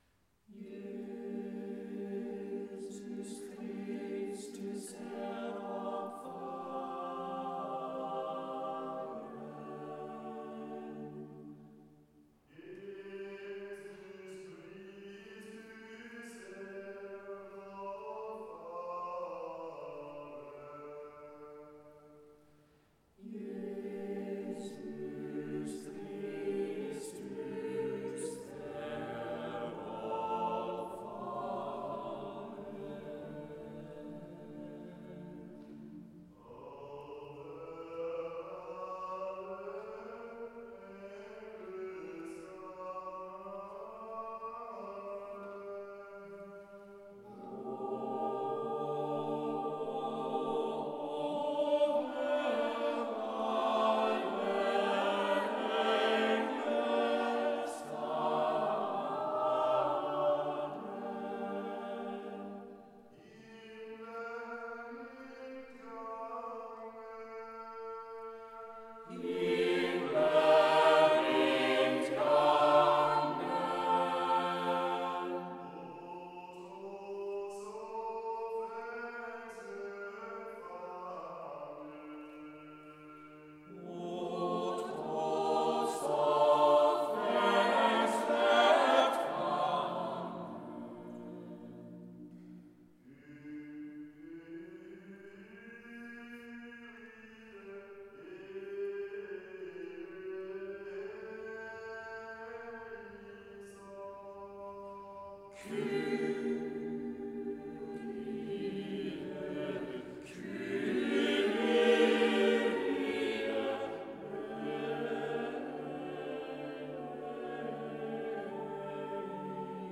Klang - Chor Vokalensemble Capella Moguntina, Mainz